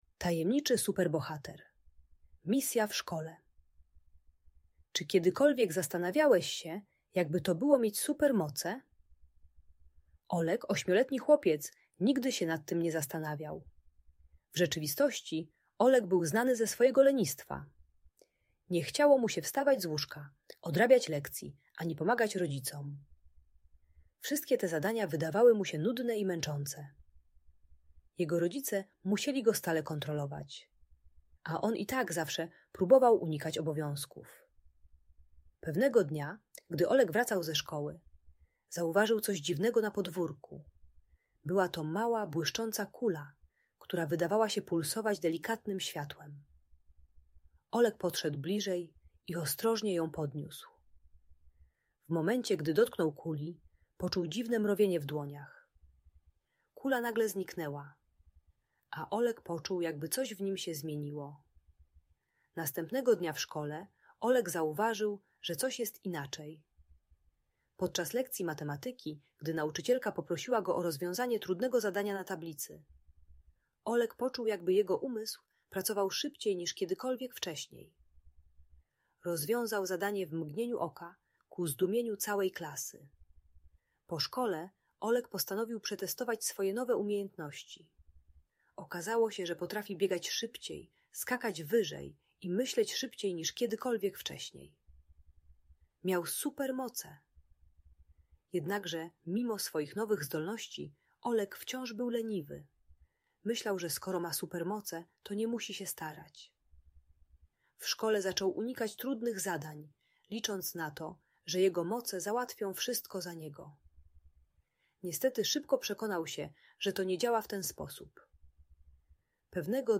Historia Tajemniczego Super Bohatera - Audiobajka